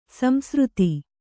♪ samsřti